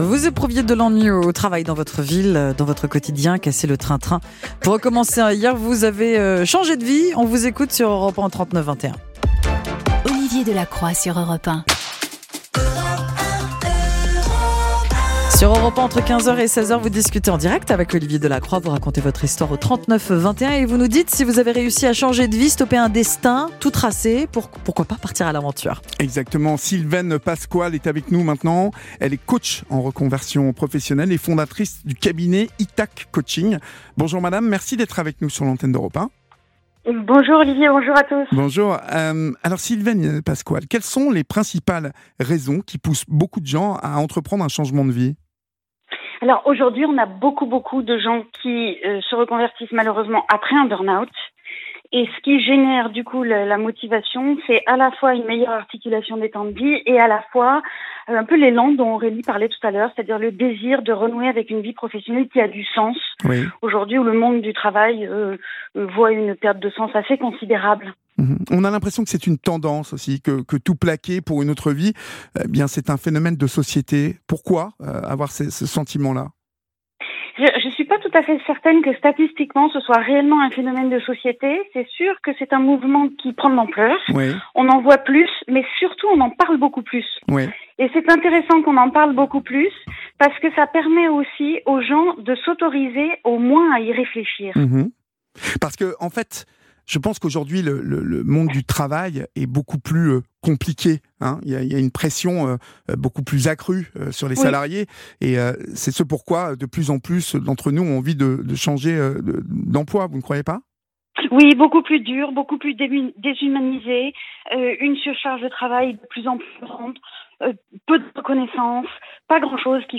J’ai été l’invitée d’Olivier Delacroix dans l’émission Partageons nos expériences de vie sur Europe 1 sur le thème Tout plaquer du jour au lendemain
Mon ITW par Olivier Delacroix